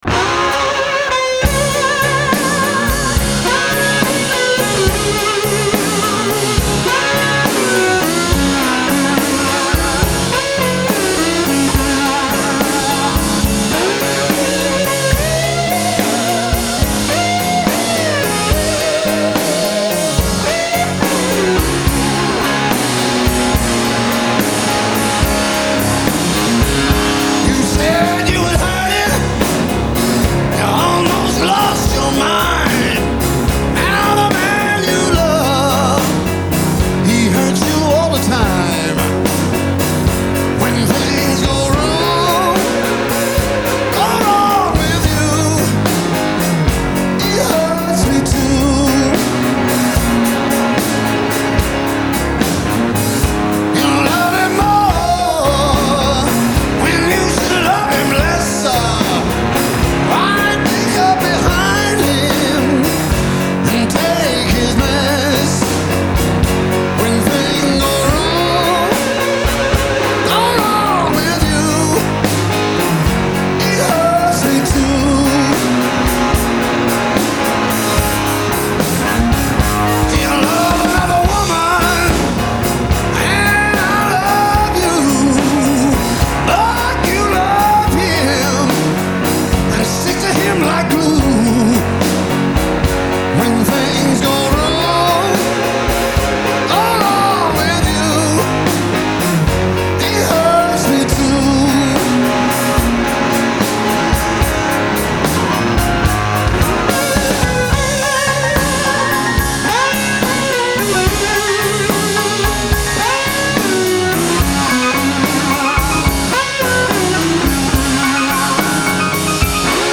Genre : Blues ,Rock
Live at the Fillmore, San Francisco, 1994